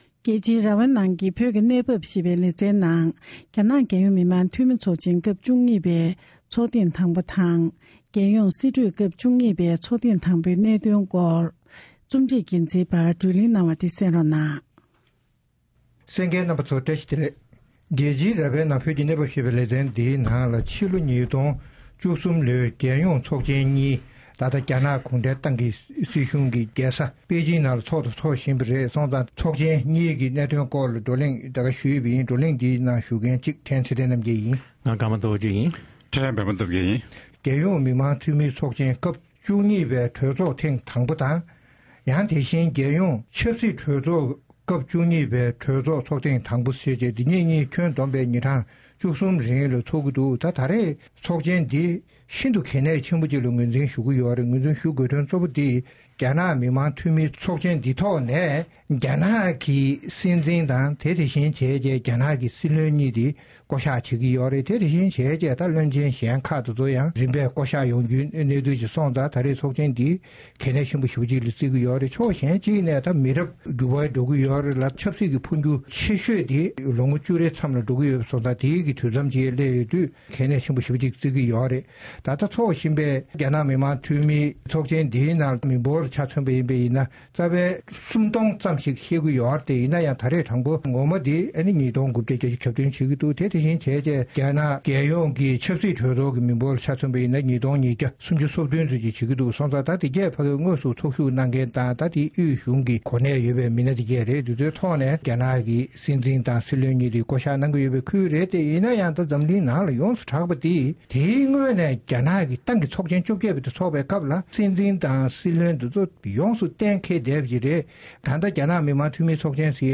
བཅས་ཀྱི་དབར་བགྲོ་གླེང་ཞུས་པ་གསན་རོགས་གནང་།།